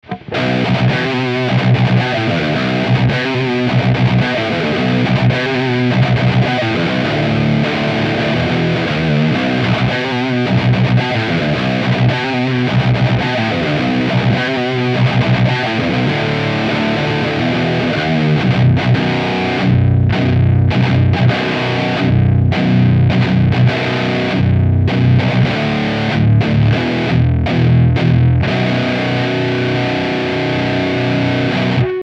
- Canal lead, Fat 5, Depth 3, Presence 6 :
Les EQ sont à midi, et le gain du lead à 2-3 sur tous les samples.
Evil Eddie_Lead 5 V5 D3 P6.mp3